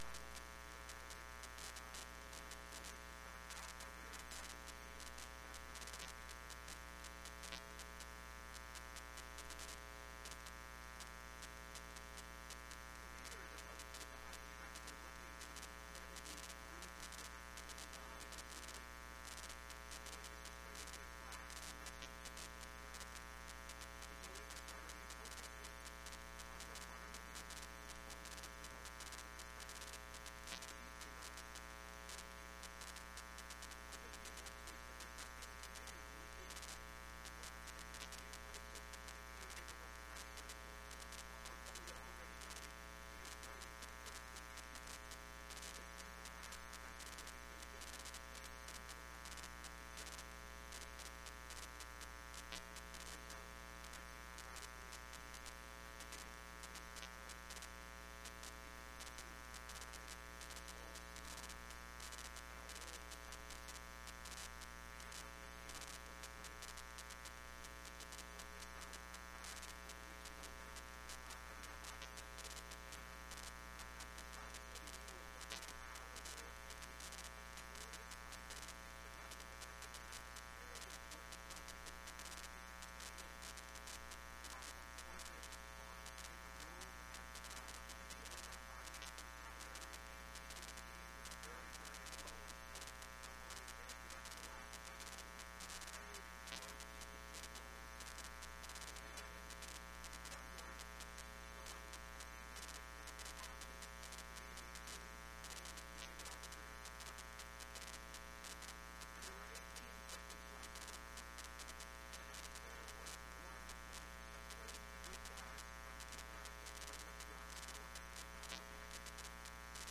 Sermons | Fort George Baptist Church